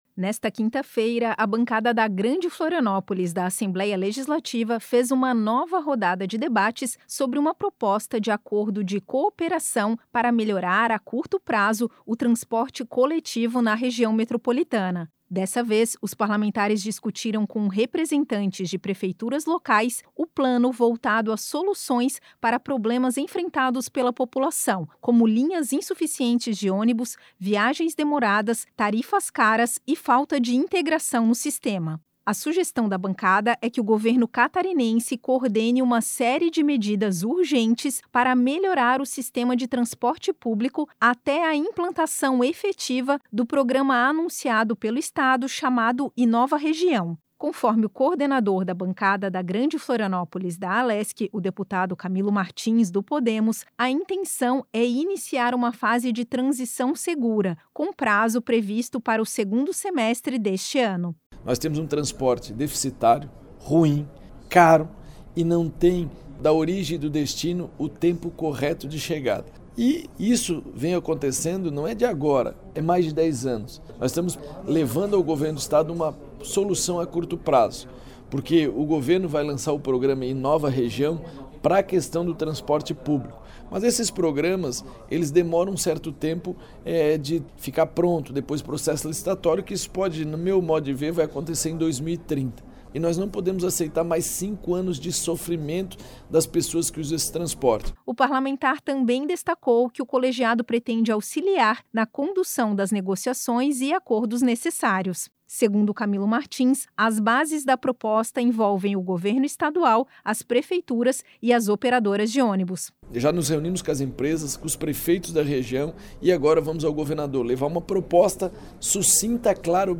Enrevistas com:
- deputado Camilo Martins (Podemos), coordenador da Bancada da Grande Florianópolis da Alesc;
- Salmir da Silva, prefeito de Biguaçu.